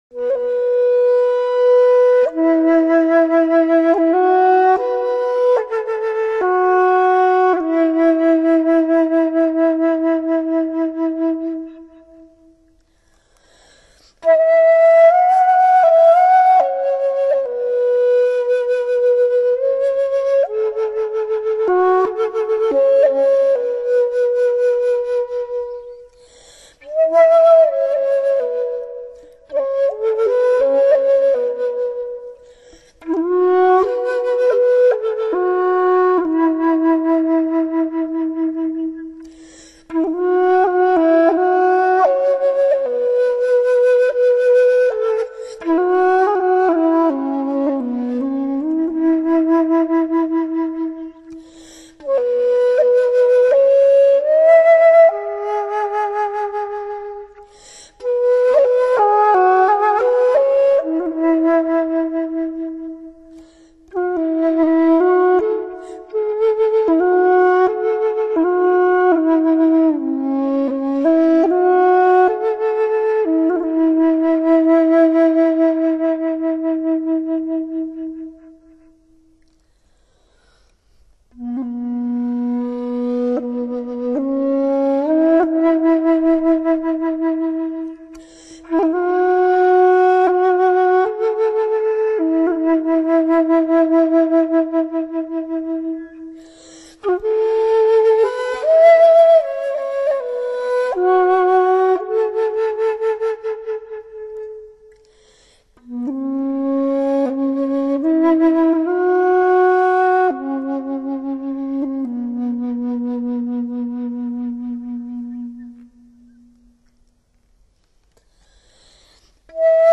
I’ll sing it first, sorry for the poor performance :)